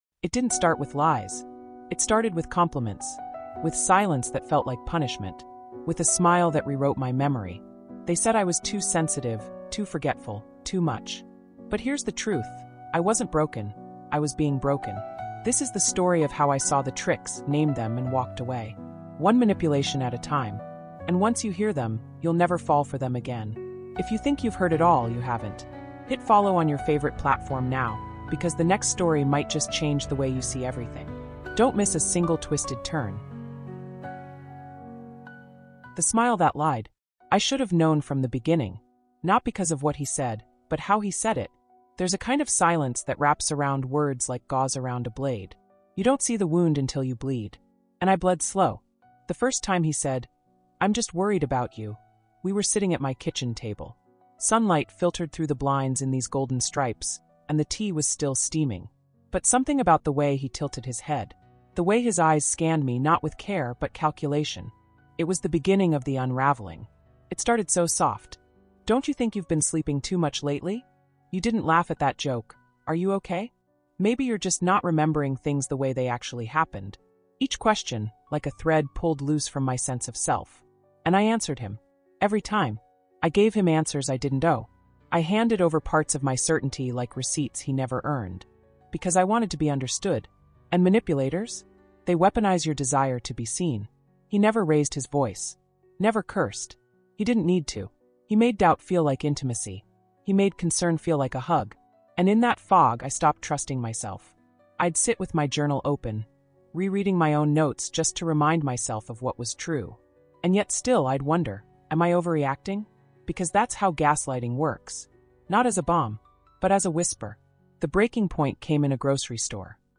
MANIPULATION: 6 Deceptive Manipulation Tricks to Avoid In this gripping, first-person psychological thriller-style audio documentary, a female narrator takes you deep into the dark world of manipulation, control, and emotional deception. Unmask the six most deceptive manipulation tactics — including gaslighting, love bombing, guilt-tripping, false redemption, emotional withholding, and mirroring — and how they quietly destroy relationships, businesses, and even self-worth.